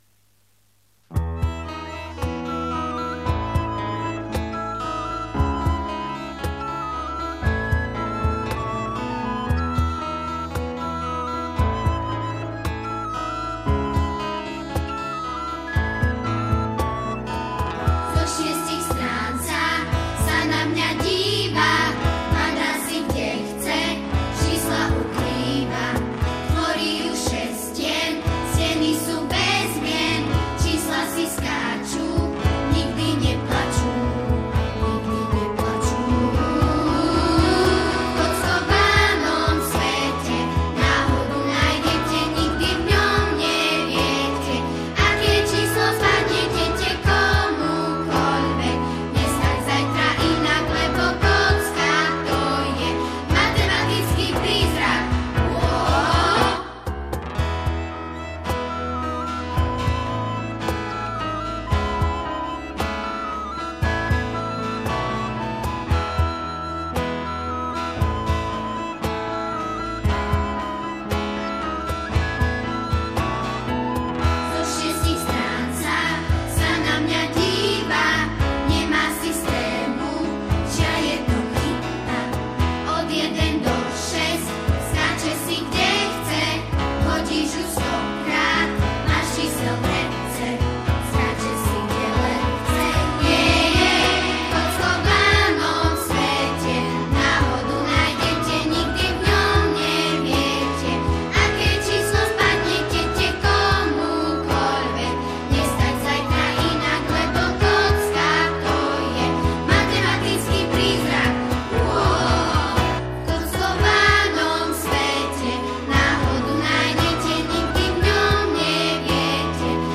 Zároveň si pesničky môžete stiahnuť ako mp3, niektoré aj v dvoch verziách, buď len samostatnú melódiu, alebo aj so spevom.
Kockovaný svet (Text, hudba: Bibiana Kľačková, Spev: žiaci SZŠ Dotyk Ružomberok)